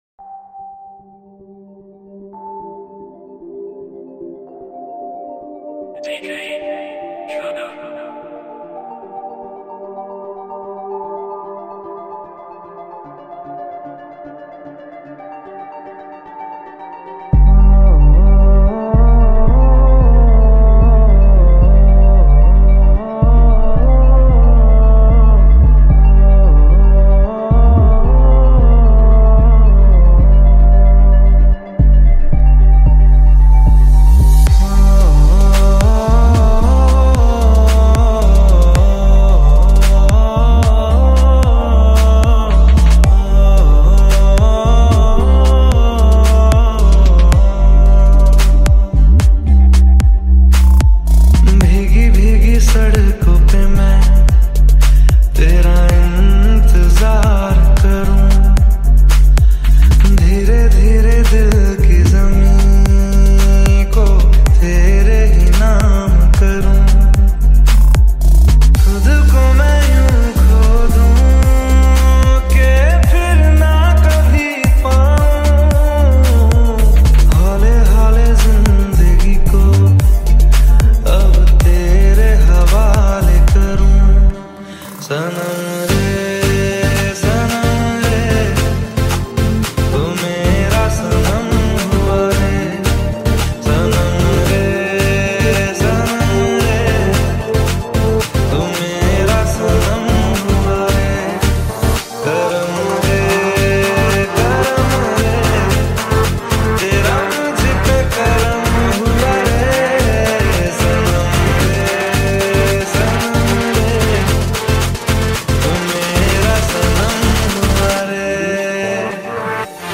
Genre - Electronic